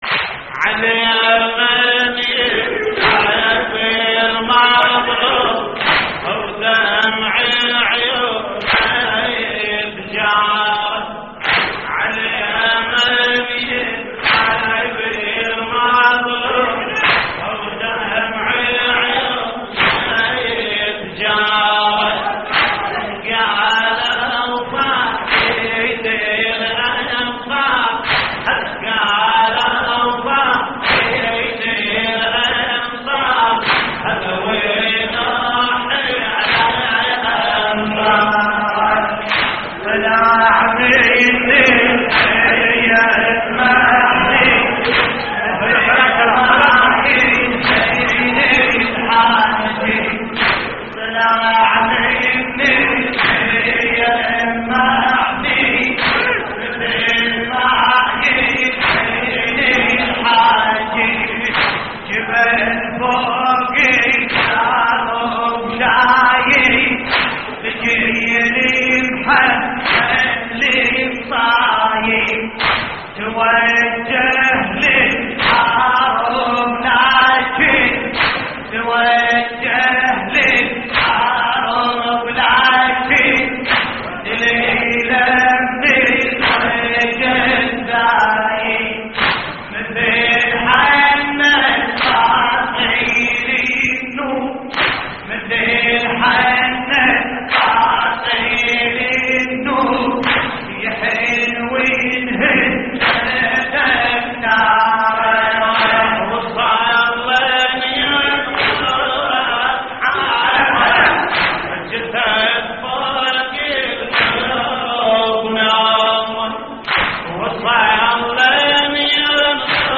تحميل : على من ينحب المظلوم ودمع عيونه يتجارى / الرادود باسم الكربلائي / اللطميات الحسينية / موقع يا حسين